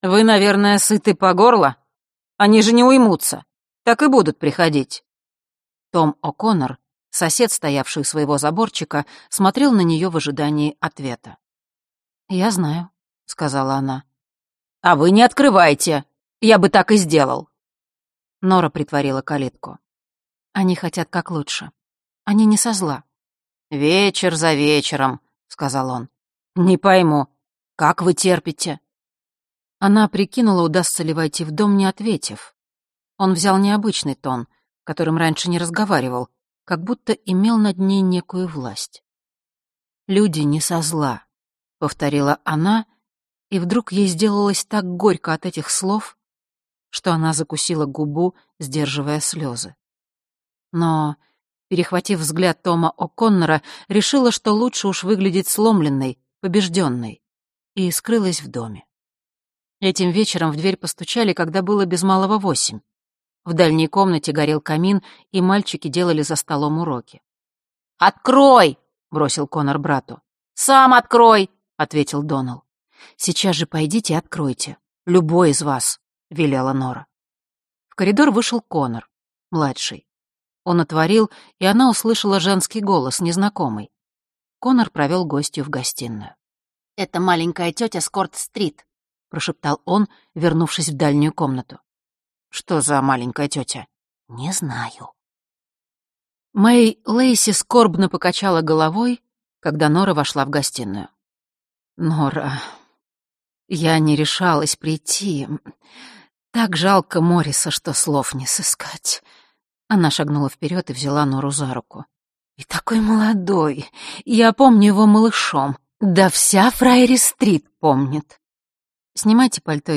Прослушать фрагмент аудиокниги Нора Вебстер Колм Тойбин Произведений: 1 Скачать бесплатно книгу Скачать в MP3 Вы скачиваете фрагмент книги, предоставленный издательством